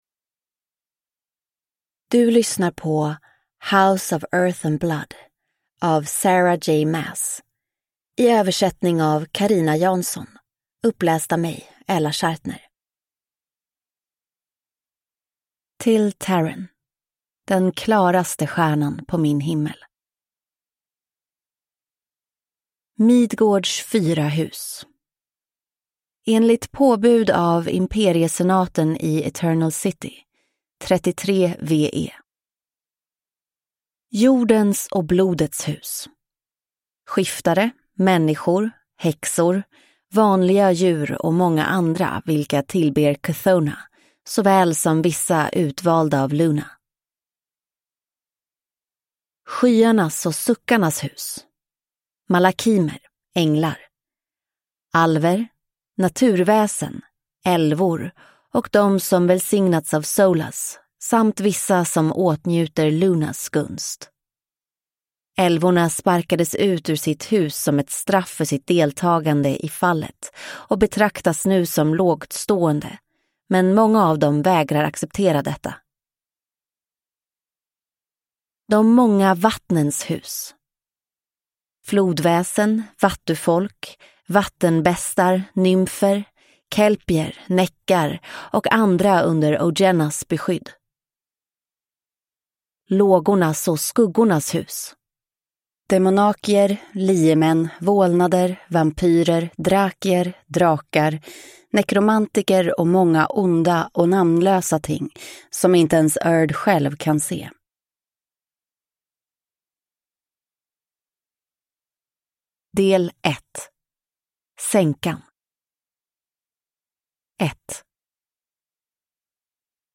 House of Earth and Blood (Svensk utgåva) – Ljudbok